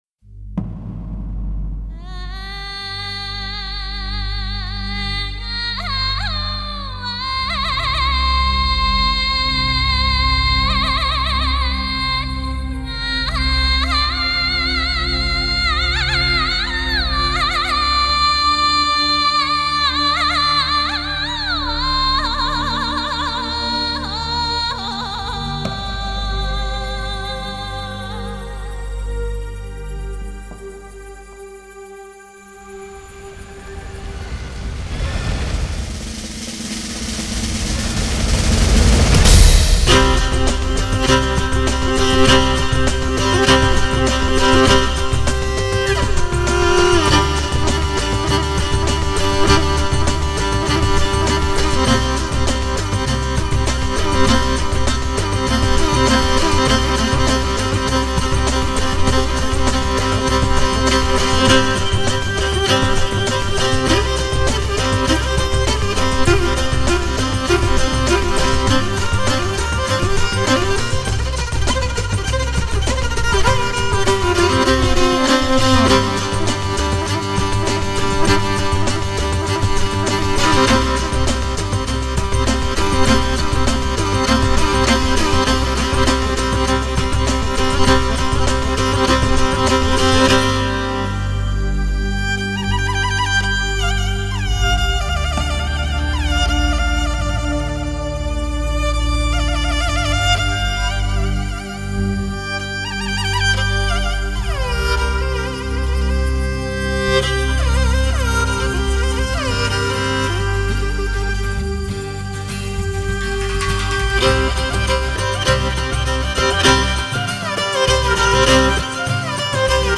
独具草原气息的民族文化，极富突破性的流行风格。